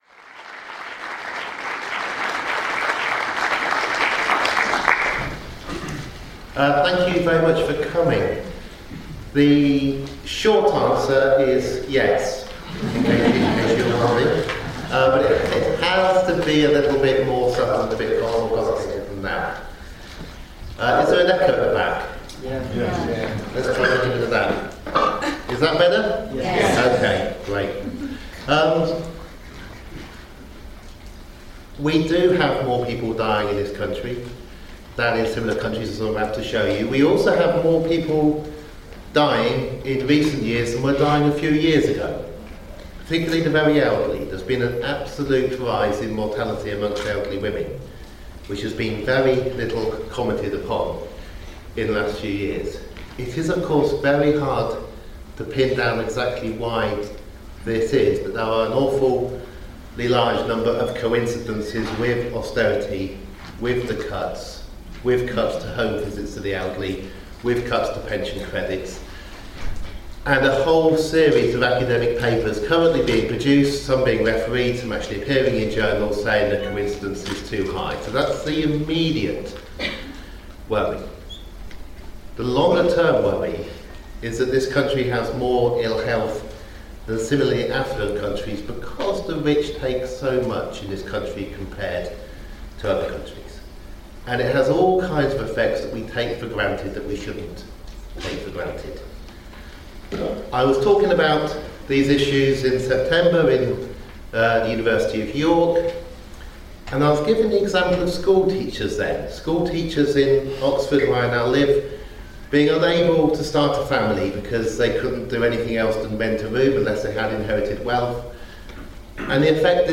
Annual Public Health Lecture, University of Southampton, January 14th 2016